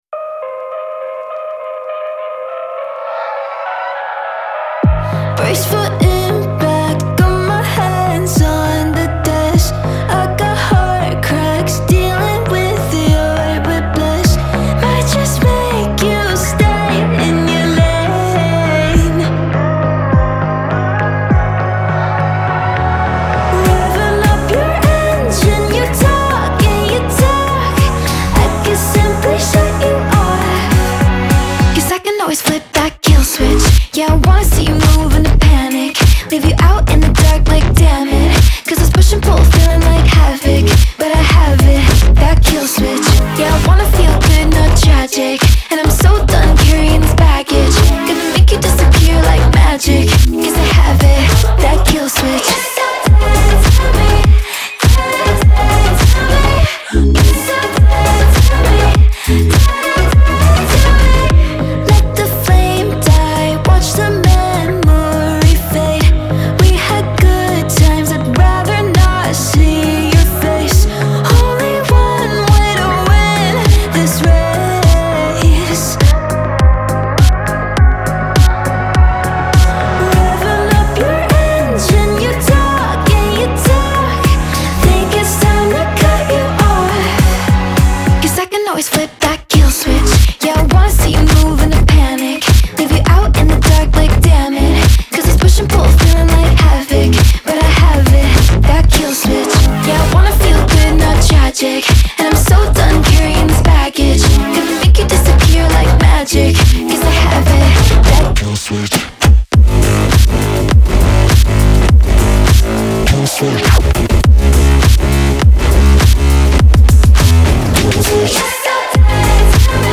BPM102-102
Audio QualityPerfect (High Quality)
Full Length Song (not arcade length cut)